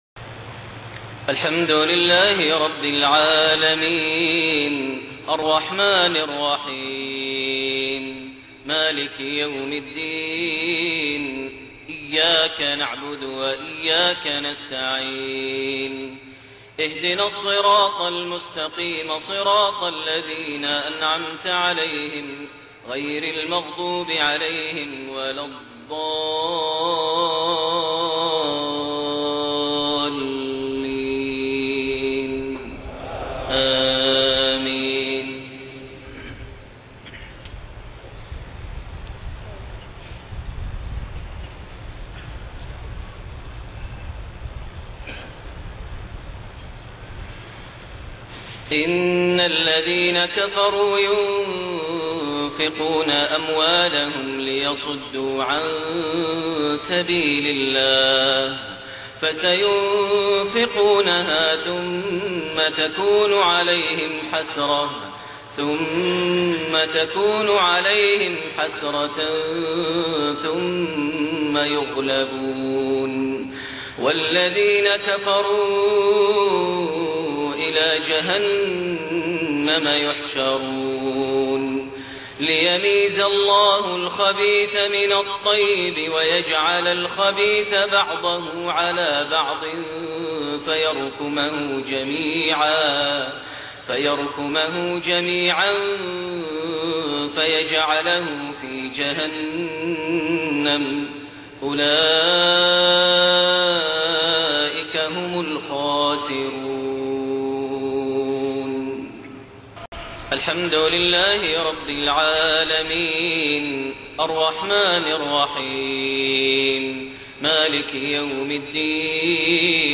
صلاة المغرب 7 محرم 1430هـ من سورة الأنفال 36-40 > 1430 🕋 > الفروض - تلاوات الحرمين